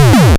Cannon.wav